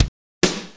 drums.mp3